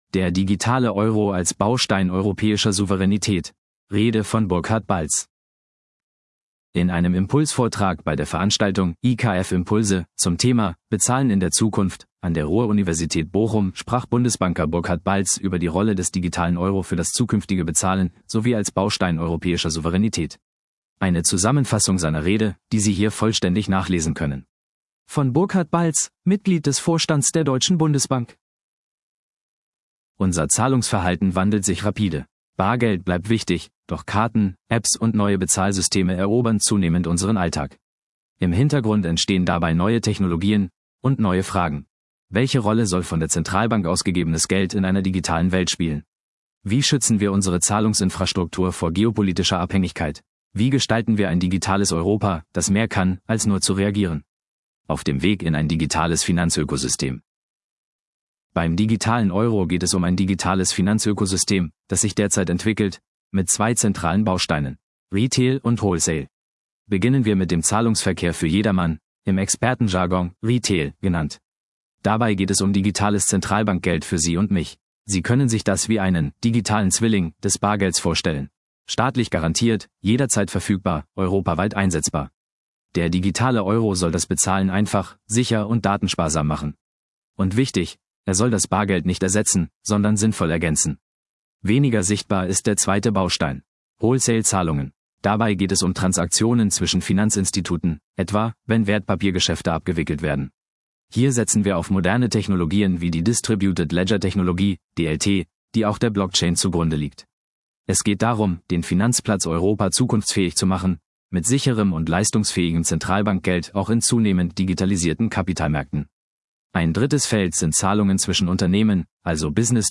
Der digitale Euro als Baustein europäischer Souveränität – Rede von Burkhard Balz
In einem Impulsvortrag bei der Veranstaltung „IKF Impulse“ zum Thema „Bezahlen in der Zukunft“ an der Ruhr-Universität Bochum sprach Bundesbanker Burkhard Balz über die Rolle des digitalen Euro für das zukünftige Bezahlen sowie als Baustein europäischer Souveränität.